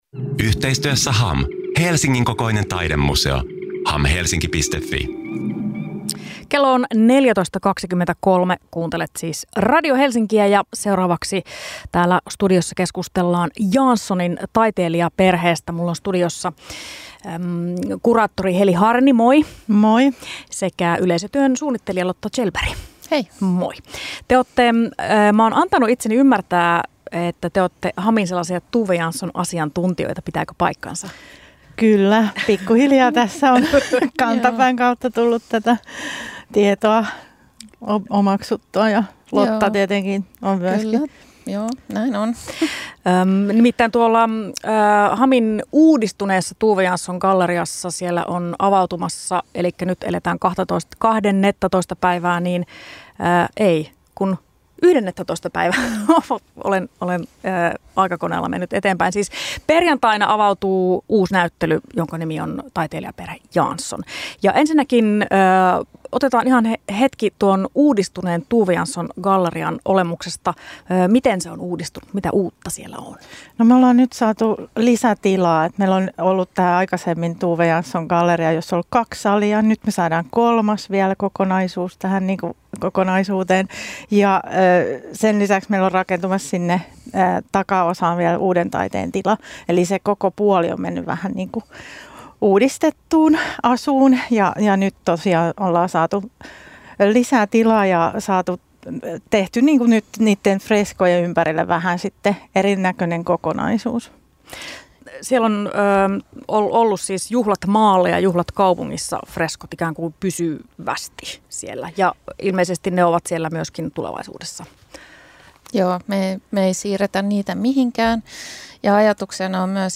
HAM Helsingissä aukeaa uudistettu Tove Jansson -galleria 13.2. Haastattelussa